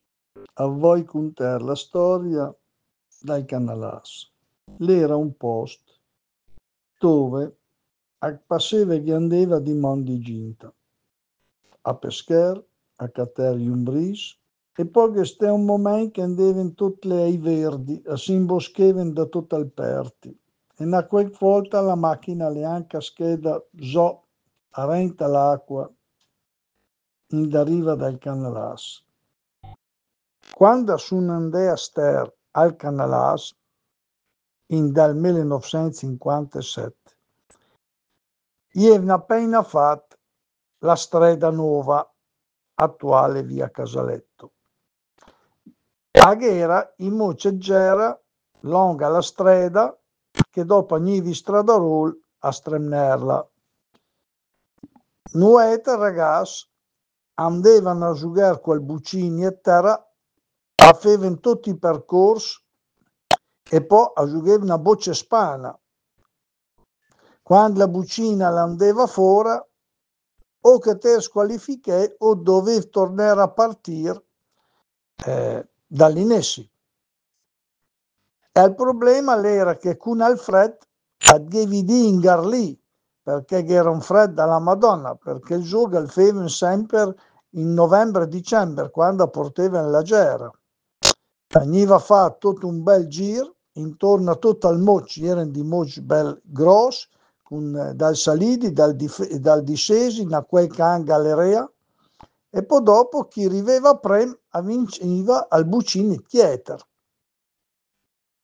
Tuttavia, in seconda istanza, abbiamo anche sfruttato le molte conoscenze dirette che abbiamo, come gruppo di Léngua Mêdra, con persone parlanti dialetti ben caratterizzati.